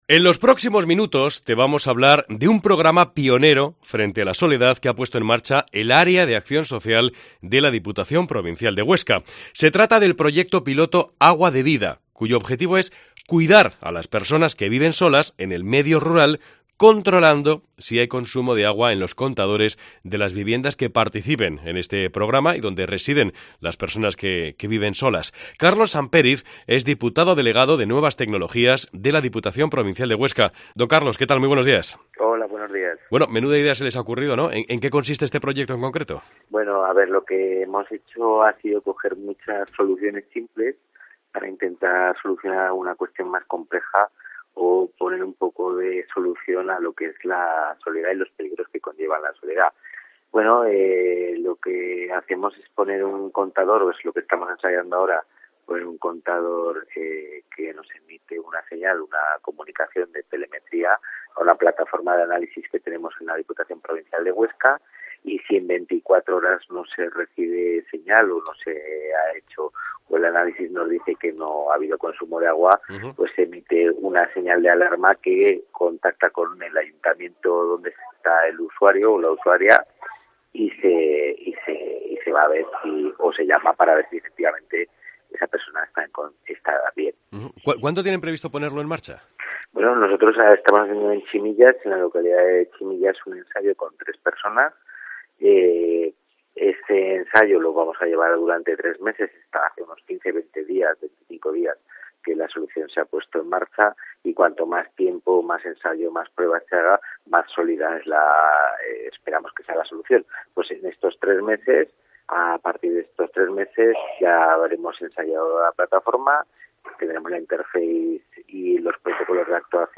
Entrevista al diputado delegado de Nuevas Tecnologías de la Diputación de Huesca, Carlos Sampériz